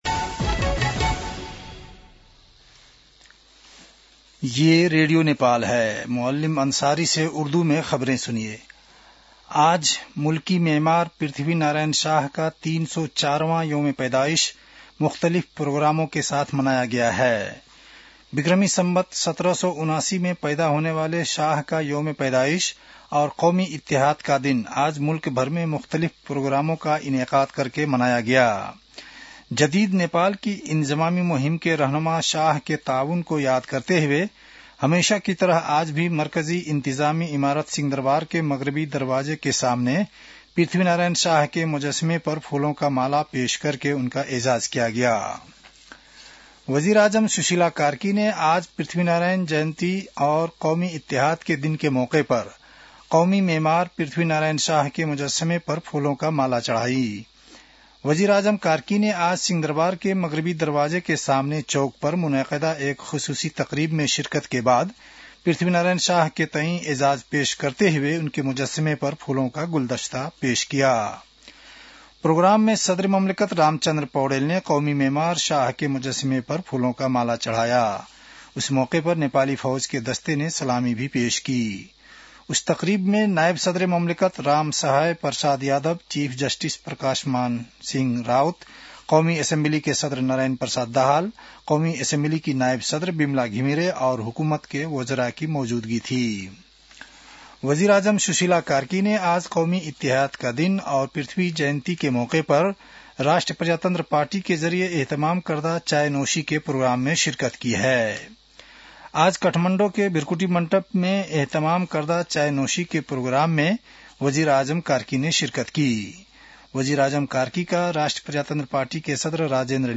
उर्दु भाषामा समाचार : २७ पुष , २०८२
Urdu-news-9-27.mp3